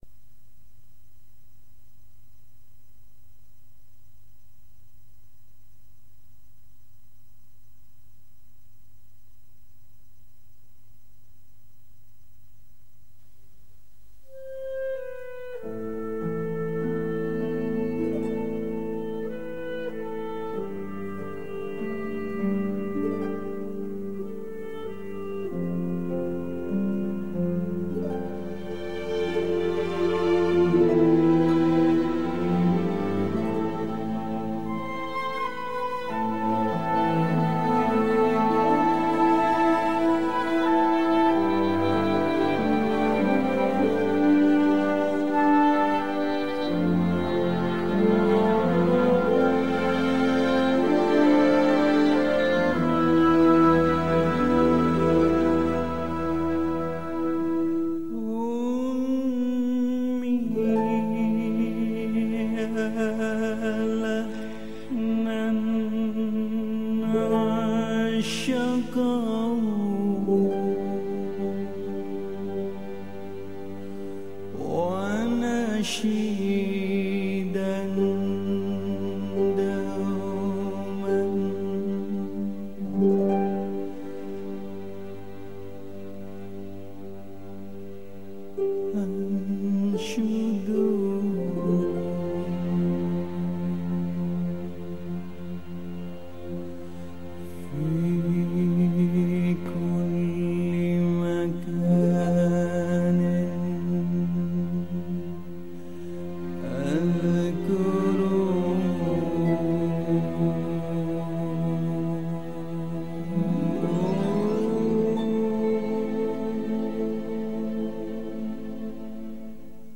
Indonesian Sufi Music